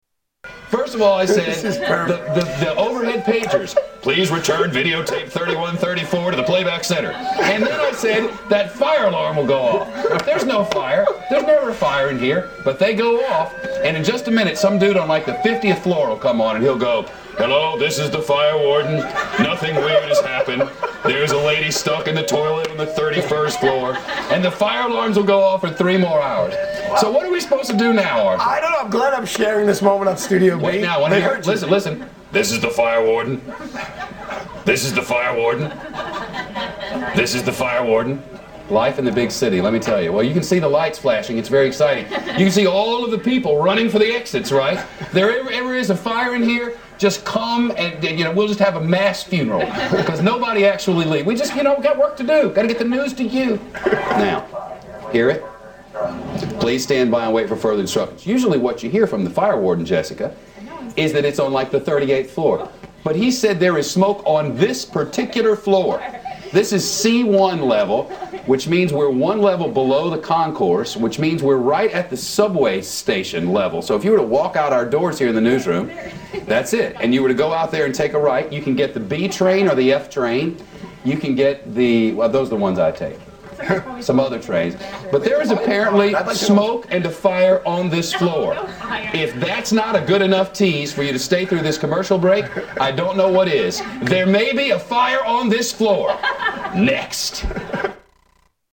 Fire Alarm Goes Off
Tags: Media Shepard Smith News Anchor Shepard Smith The Fox Report News Anchor